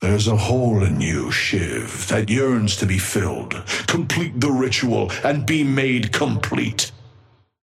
Amber Hand voice line - There's a hole in you, Shiv, that yearns to be filled.
Patron_male_ally_shiv_start_05.mp3